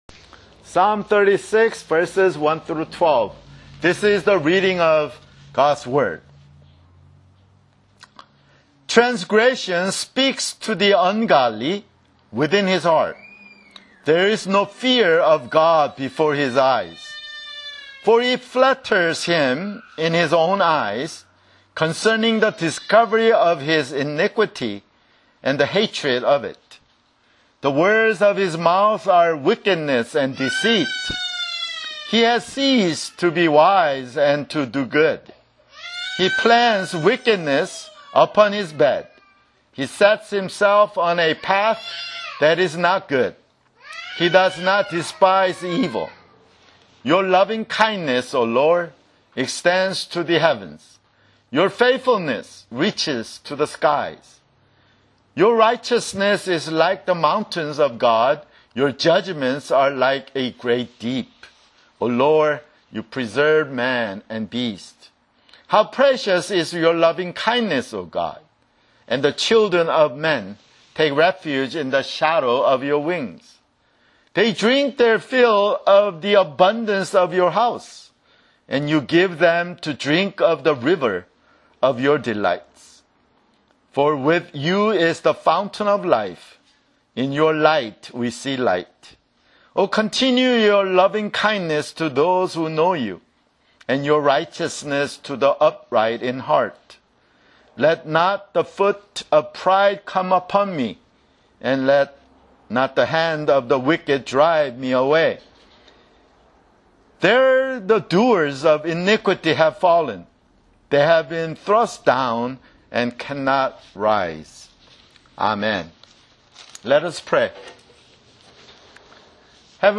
[Sermon] Psalms (28)